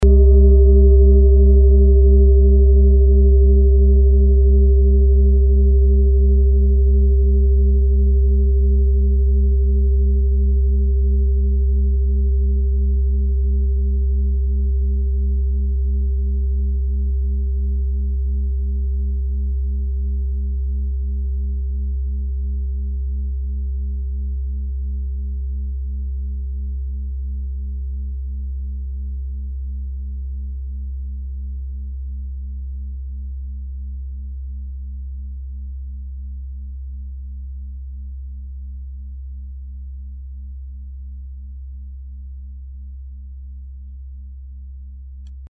Fuß-Klangschale Nr.4
Klangschale-Durchmesser: 44,1cm
Diese große Klangschale wurde in Handarbeit von mehreren Schmieden im Himalaya hergestellt.
(Ermittelt mit dem Gummischlegel)
Der Jahreston(OM) klingt bei 136,10 Hertz und in den Oktaven ober- und unterhalb. In unserer Tonleiter ist das nahe beim "Cis".
fuss-klangschale-4.mp3